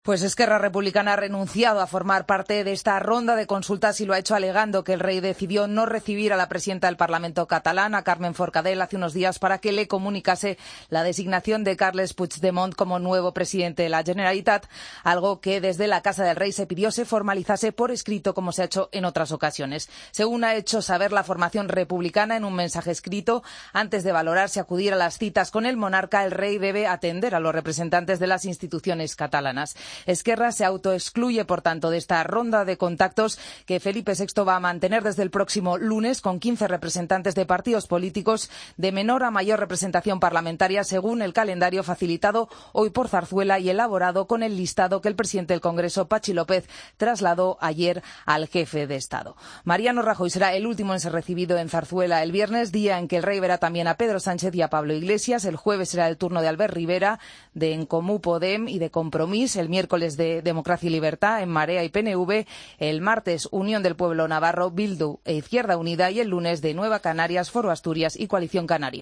AUDIO: Escucha la crónica